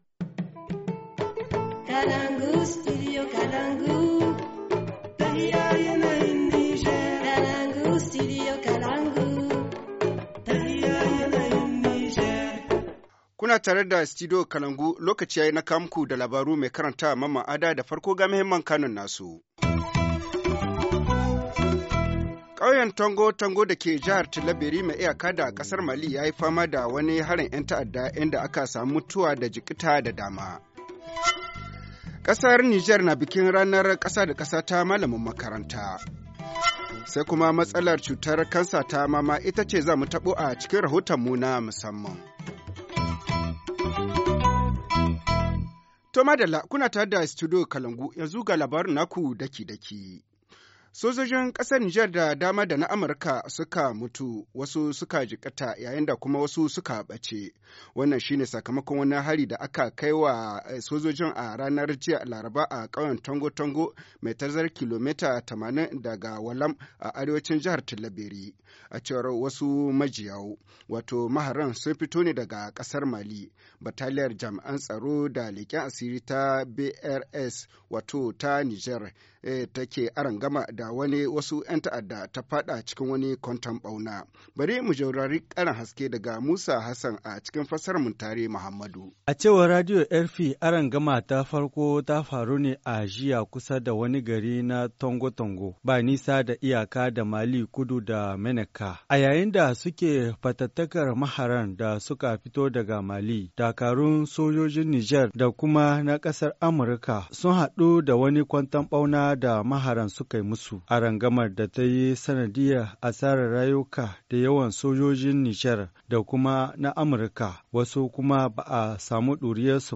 Journal du 5 octobre 2017 - Studio Kalangou - Au rythme du Niger